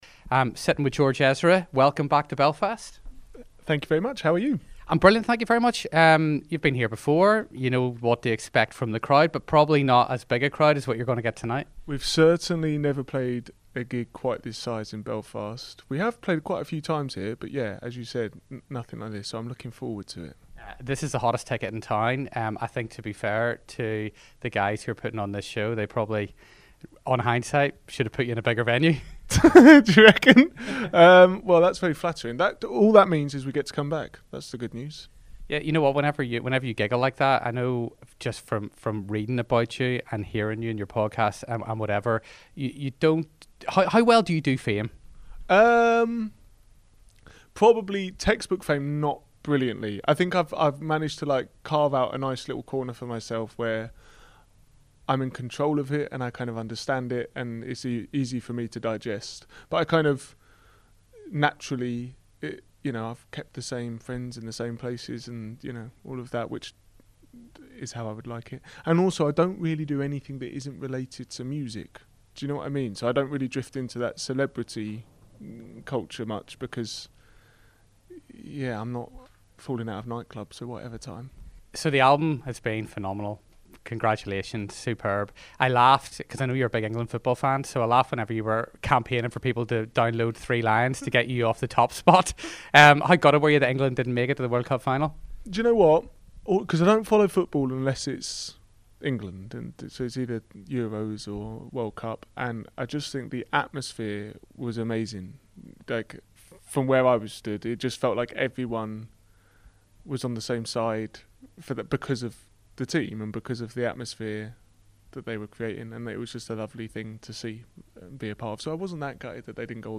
interviews George Ezra before CHSq gig